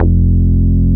P MOOG F2MP.wav